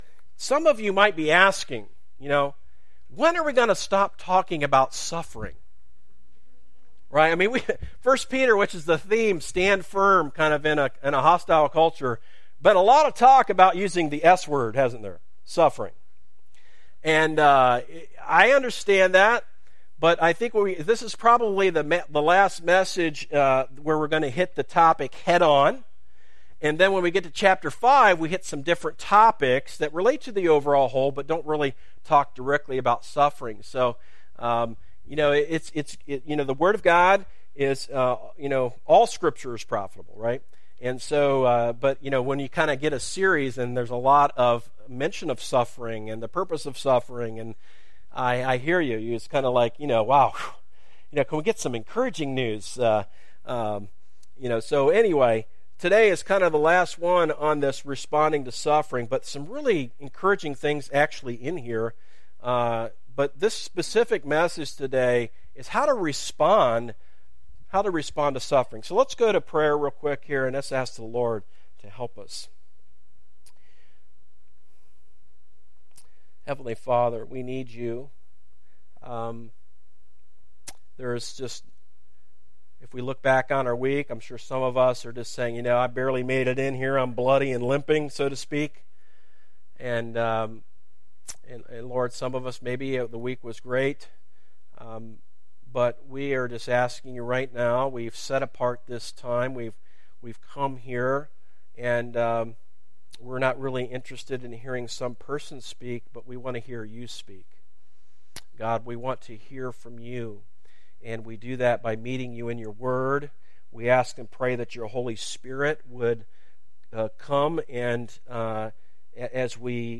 Sermons - series archive - Darby Creek Church - Galloway, OH